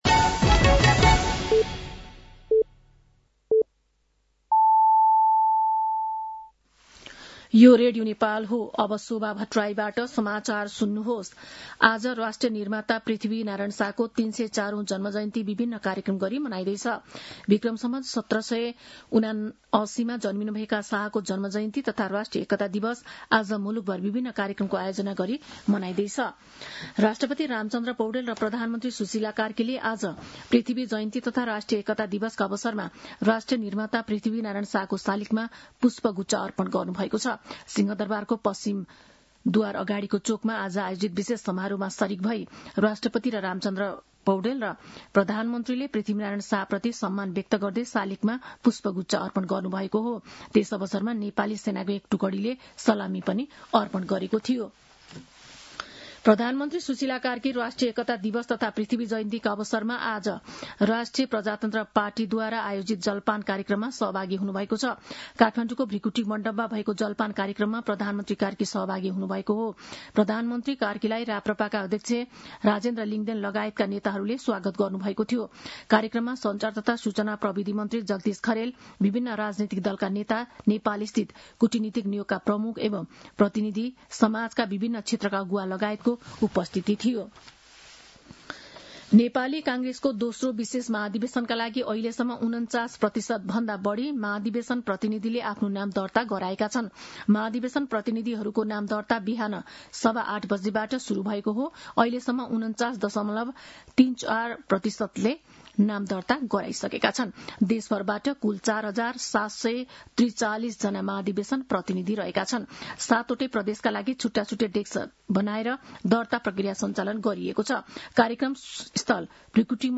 साँझ ५ बजेको नेपाली समाचार : २७ पुष , २०८२
5-pm-nepali-news-9-27.mp3